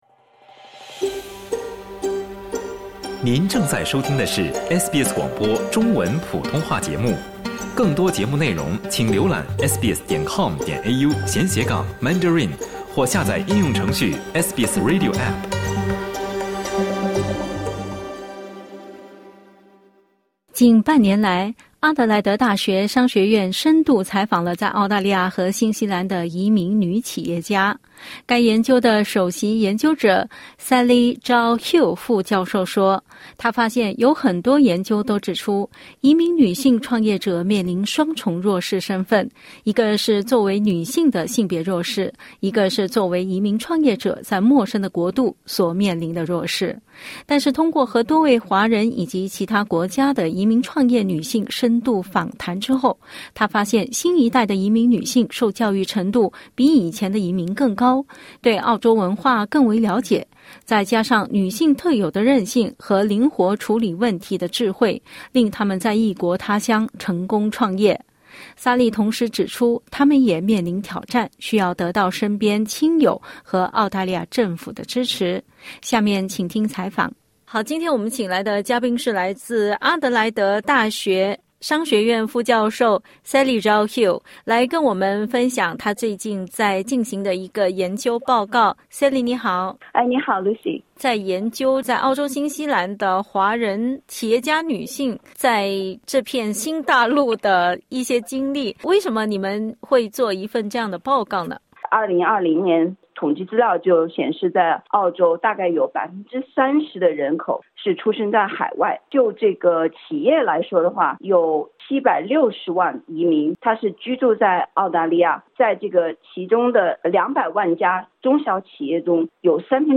（点击音频收听详细采访） 欢迎下载应用程序SBS Audio，订阅Mandarin。